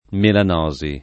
[ melan 0@ i ]